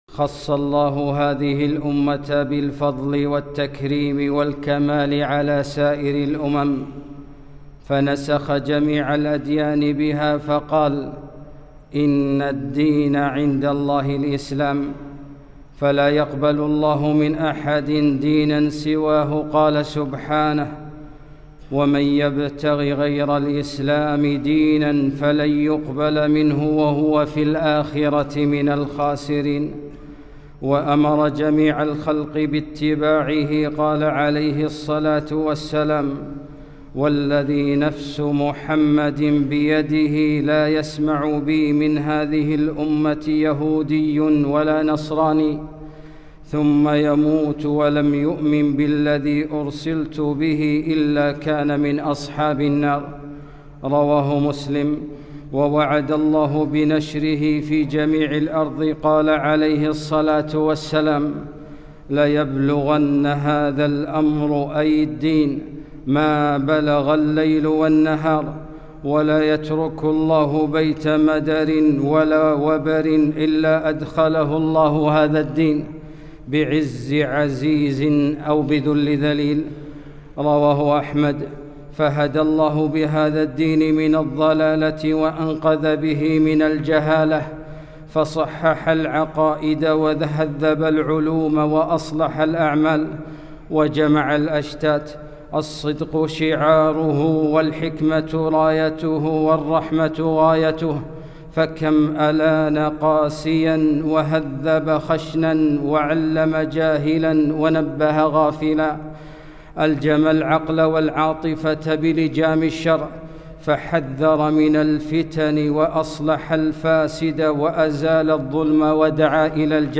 خطبة - فضل الإسلام